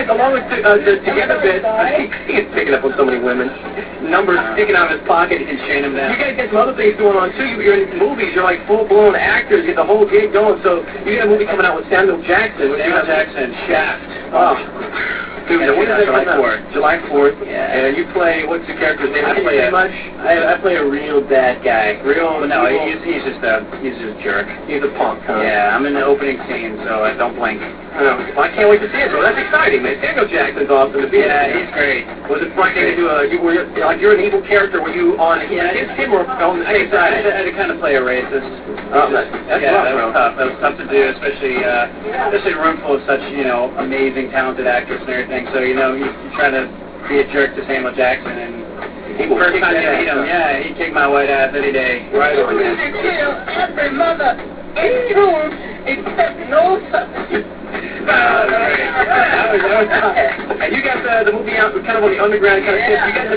RADIO INTERVIEWS